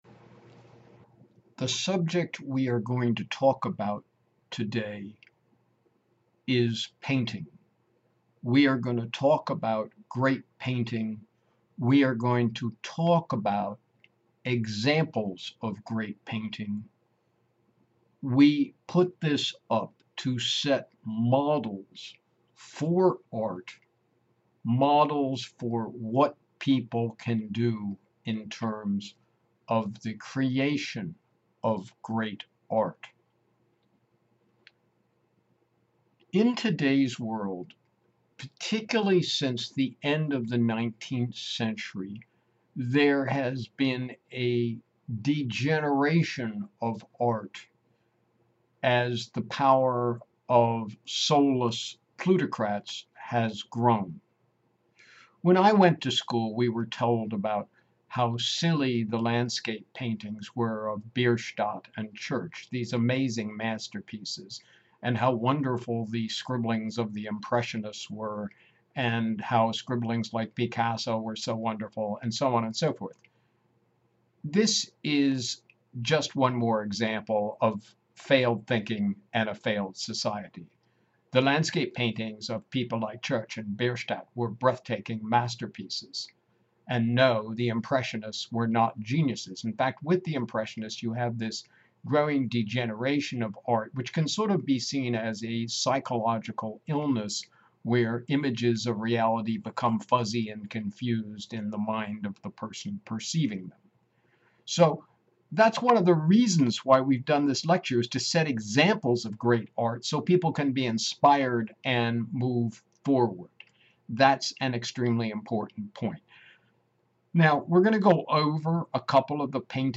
AUDIO LECTURE NOTE: AUDIO OPENS IN NEW WINDOW YOU CAN GO BACK TO MAIN WINDOW TO SEE PAINTINGS AS THEY ARE DISCUSSED IN LECTURE.